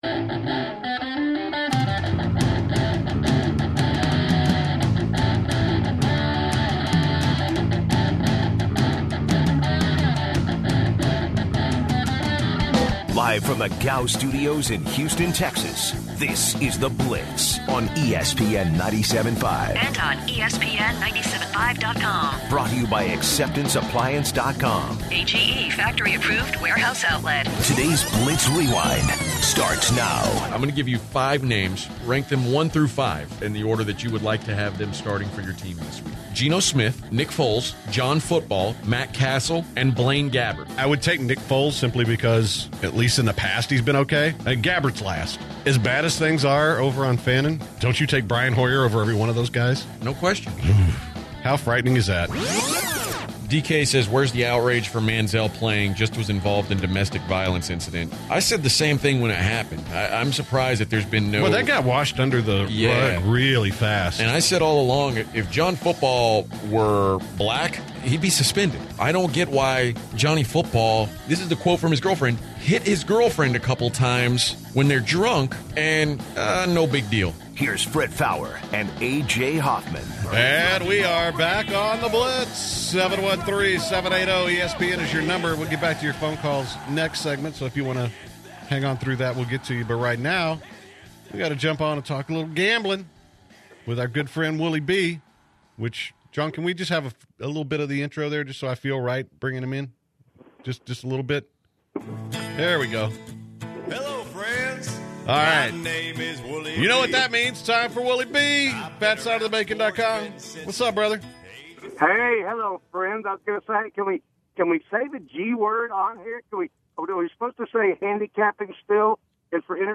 interviews UFC fighter Vitor Belfort regarding his upcoming fight against Dan Henderson.